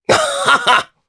Oddy-Vox_Happy1_jp.wav